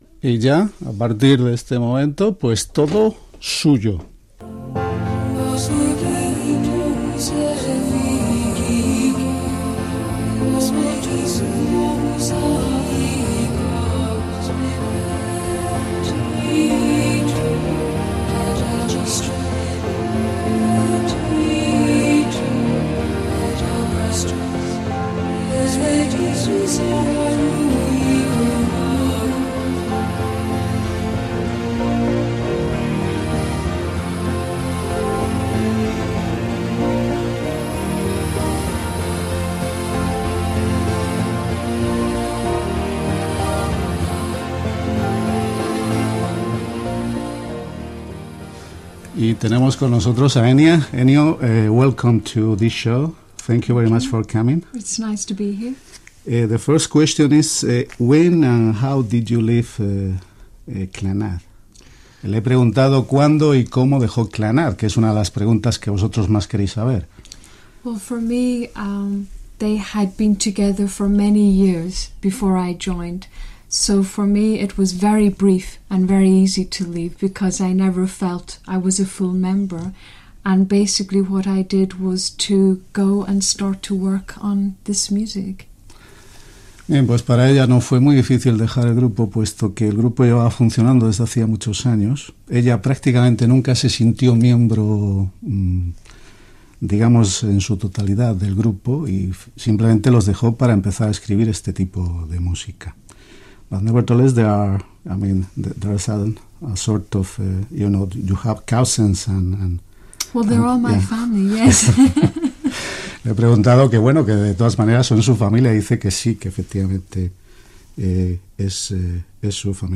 Entrevista a Enya (Eithne Pádraigín Ní Bhraonáin): cantant i compositora irlandesa de música New Age